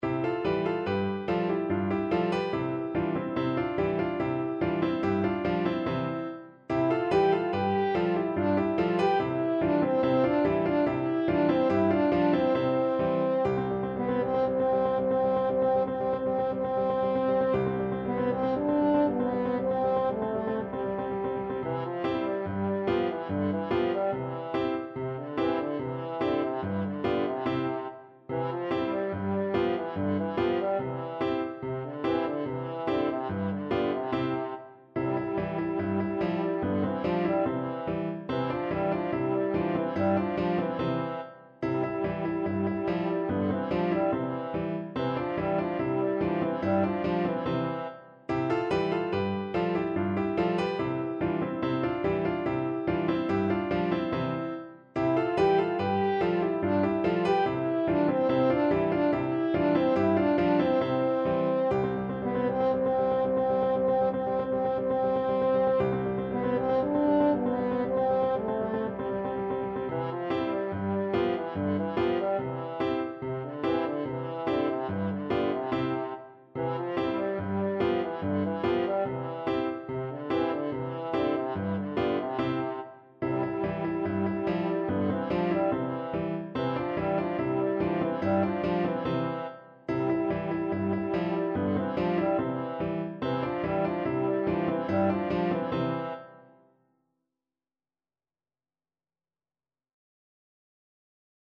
World Trad. Sikon (Opa Ni Na Nai) (Greek Folk Song) French Horn version
French Horn
C major (Sounding Pitch) G major (French Horn in F) (View more C major Music for French Horn )
4/4 (View more 4/4 Music)
Cheerfully =c.72
World (View more World French Horn Music)